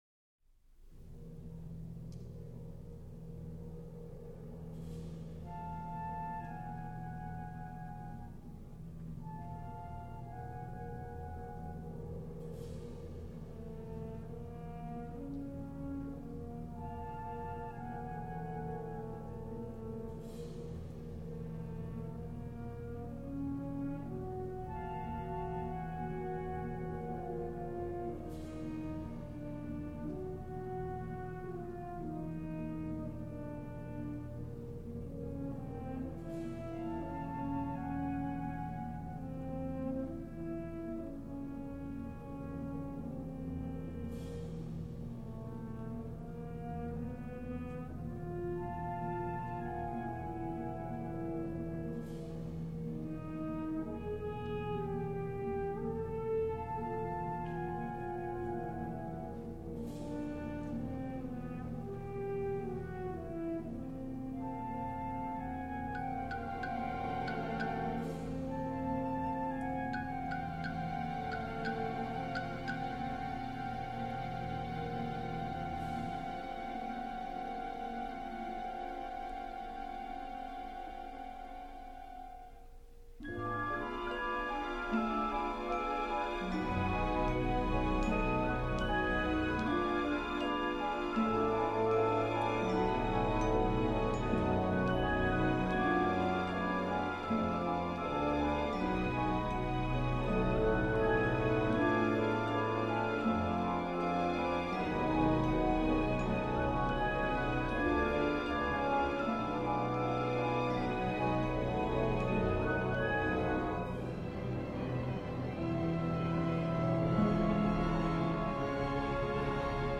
L’orchestration de cette oeuvre en cinq mouvements -les deux derniers sont enchaînes- fait appel, outre l’orchestre classique traditionnel, à une soliste soprano qui chante sans paroles, à un modeste chœur de femmes à trois voix et à un large instrumentarium avec orgue, piano machine à vent et un panel varié de percussions.